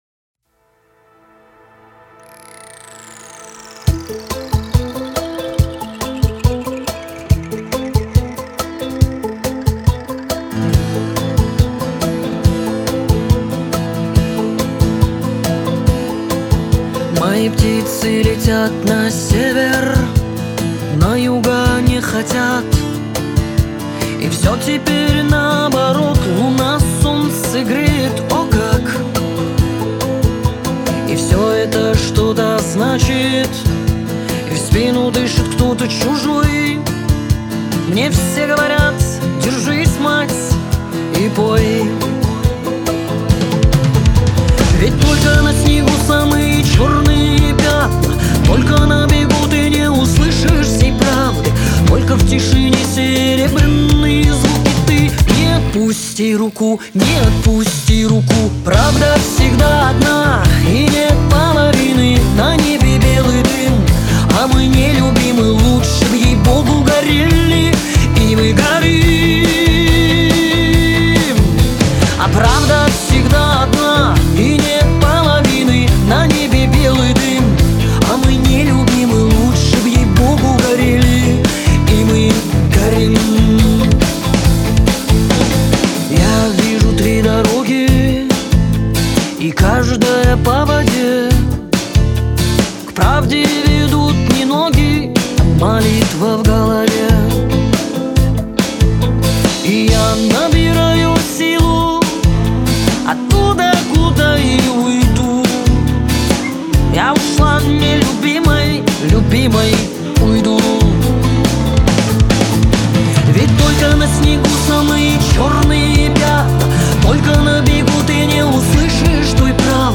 отличается насыщенной мелодией и душевным вокалом